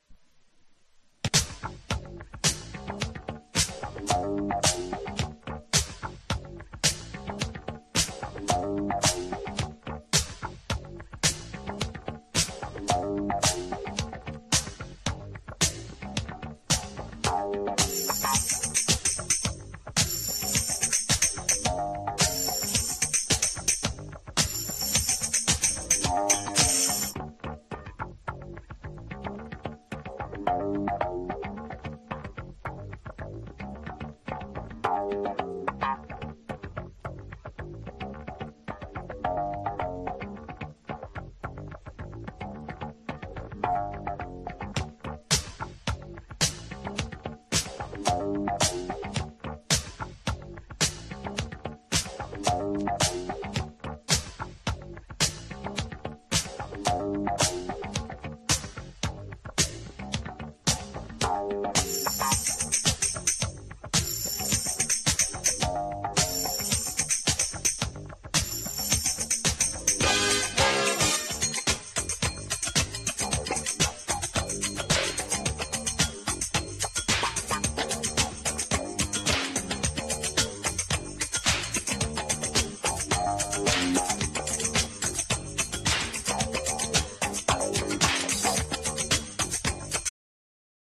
# NU-DISCO / RE-EDIT